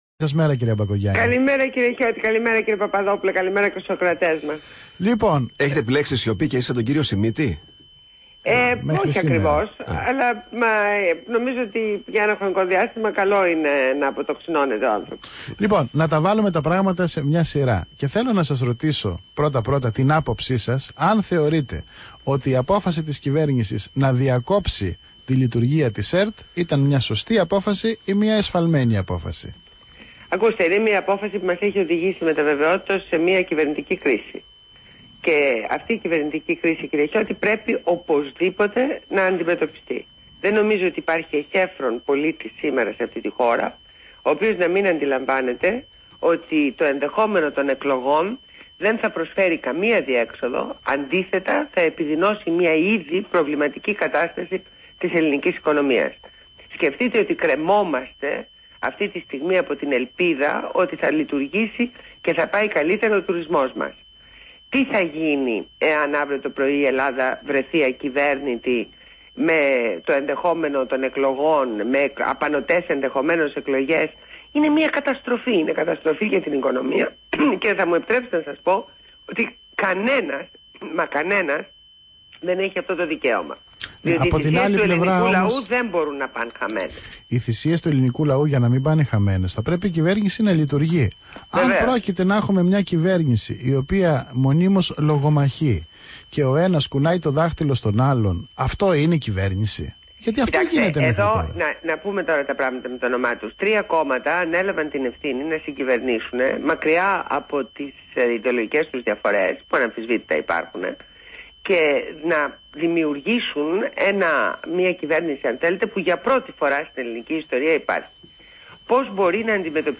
Συνέντευξη στο ραδιόφωνο ΒΗΜΑ FM